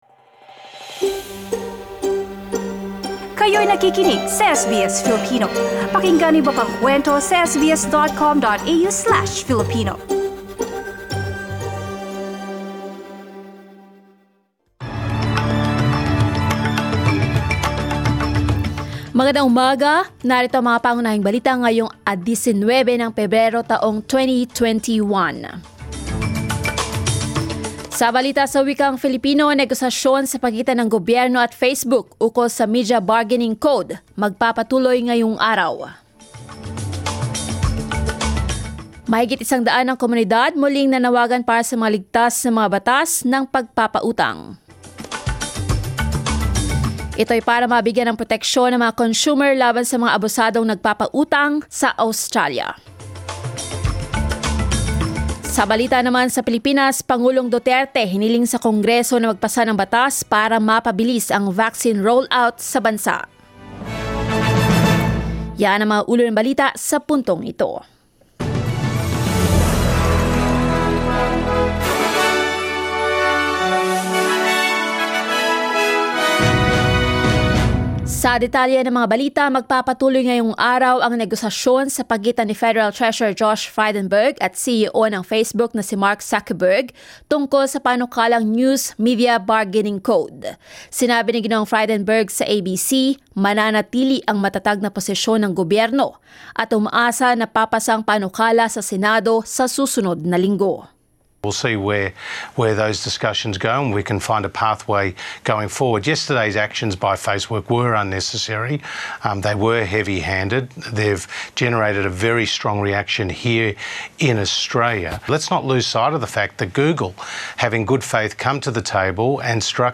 SBS News in Filipino, Friday 19 February